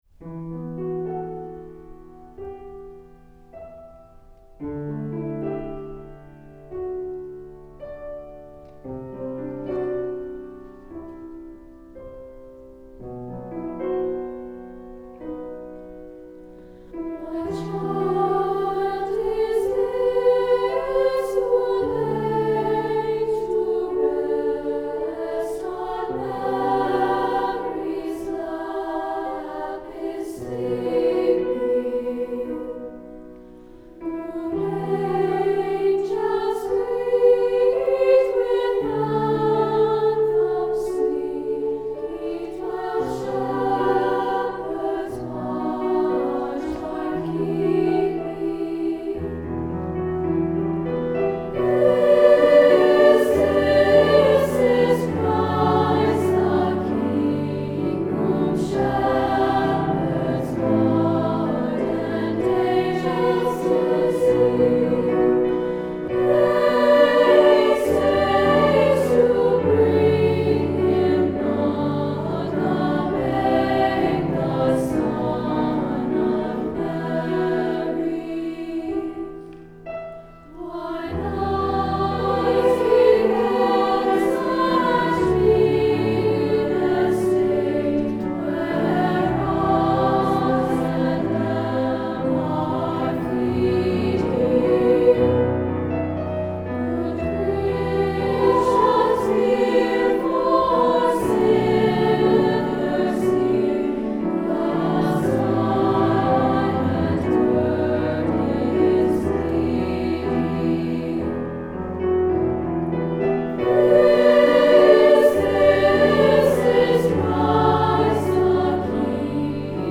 Voicing: Un/2/3pt